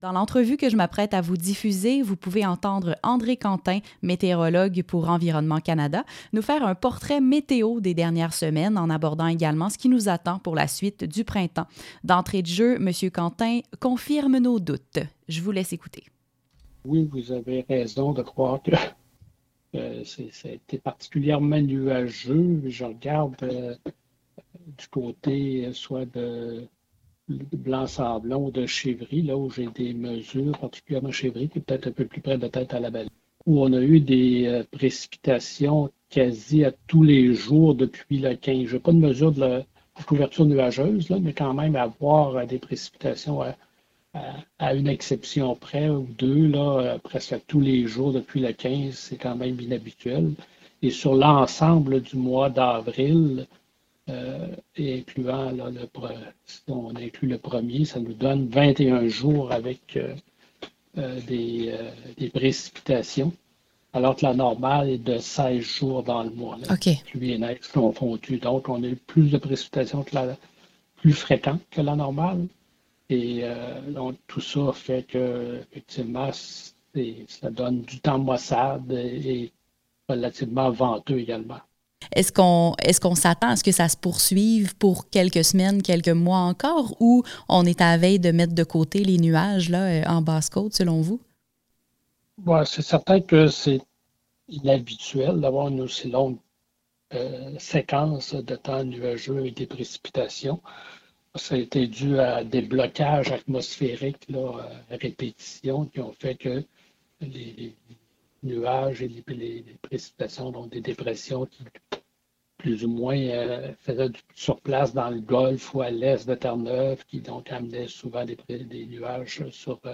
Météo-segment-radio.mp3